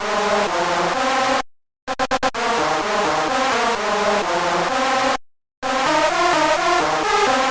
标签： 128 bpm House Loops Brass Loops 1.26 MB wav Key : Unknown
声道立体声